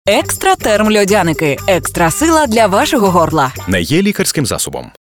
Для підвищення частоти контакту в ефірі було додатково використано коротку 5-секундну версію з лаконічним і легко впізнаваним меседжем: «Екстратерм льодяники — екстра сила для вашого горла».